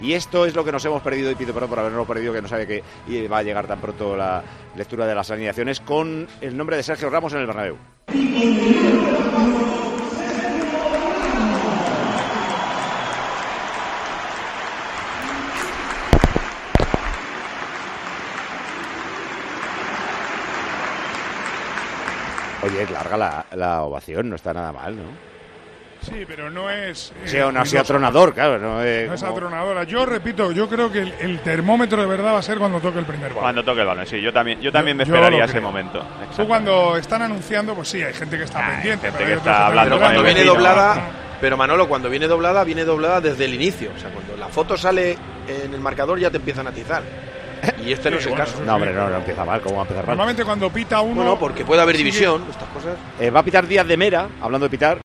Un reencuentro entre Ramos y Santiago Bernabéu que comenzó con una pequeña ovación de la afición al central del Sevilla cuando fue nombrado por megafonía. Así vivimos ese momento en Tiempo de Juego con los comentarios de Paco González y Manolo Lama.
La ovación a Ramos del Santiago Bernabéu